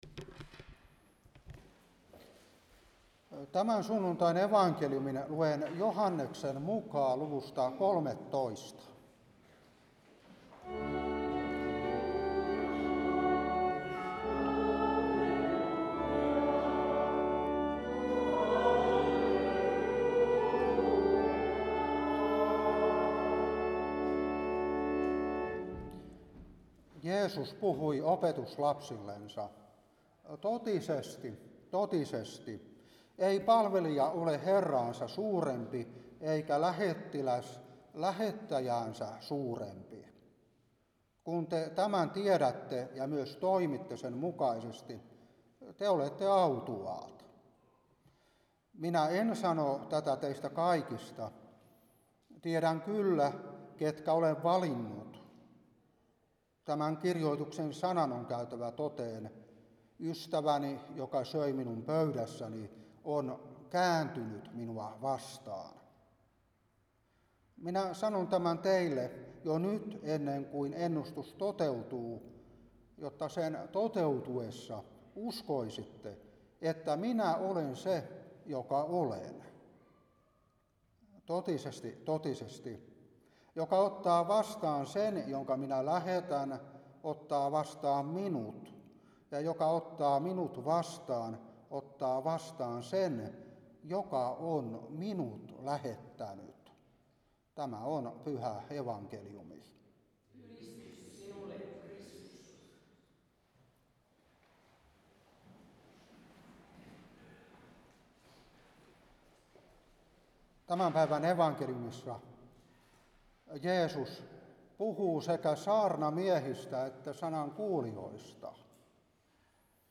Saarna 2024-10.